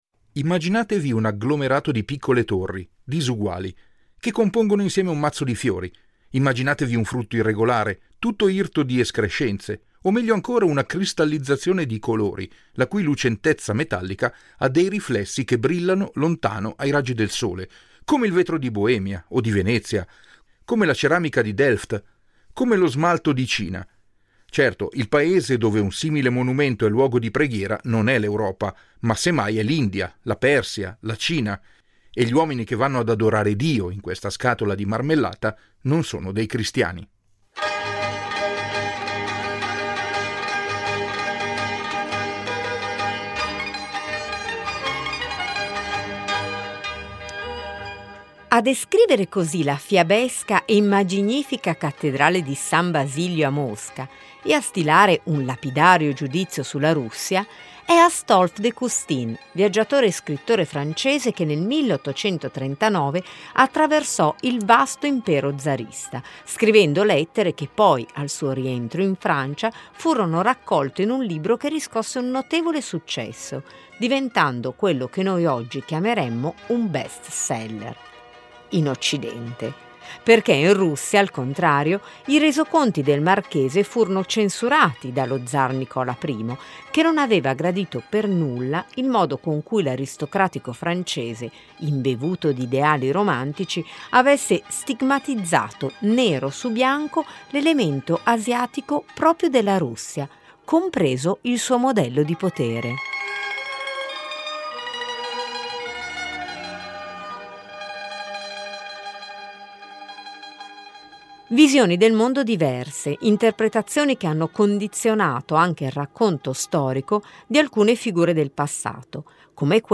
A Vologda incontriamo anche un altro appassionato della figura di Ivan IV: